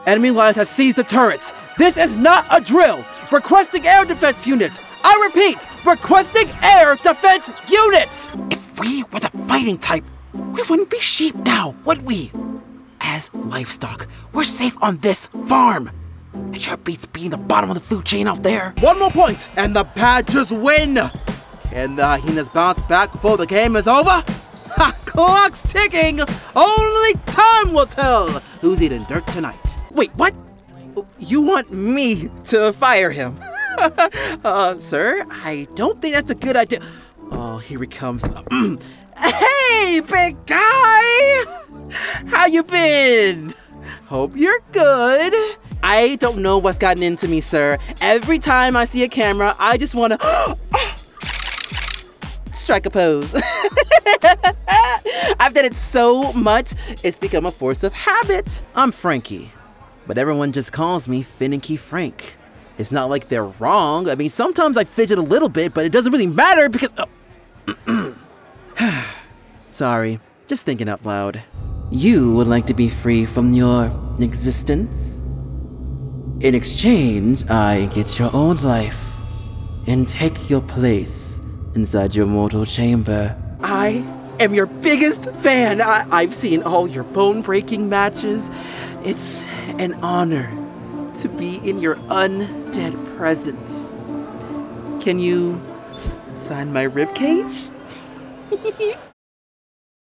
Teenager, Young Adult, Adult
I do have a home Studio that consist of studio foam, moving blankets and more, and have a quick turn around!
black us
standard us | natural
ANIMATION 🎬